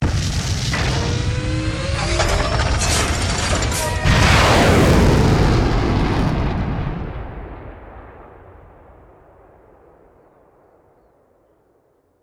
launch.wav